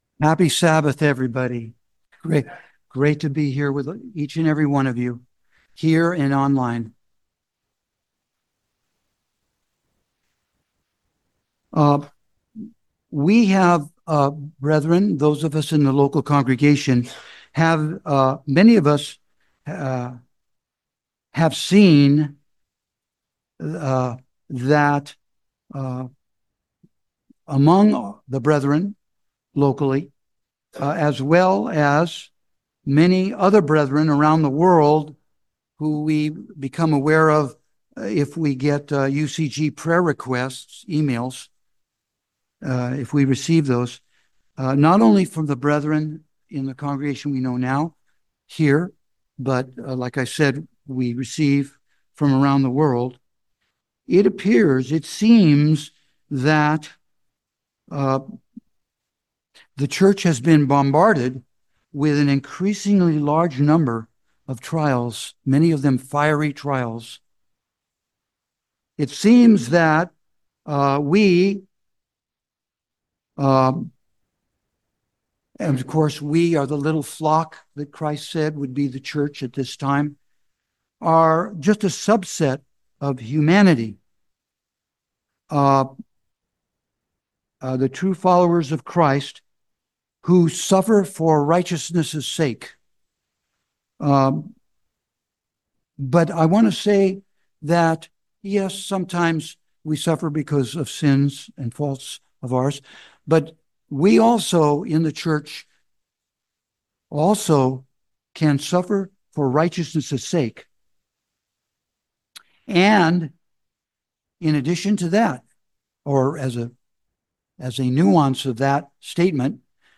Sermons
Given in San Francisco Bay Area, CA San Jose, CA Petaluma, CA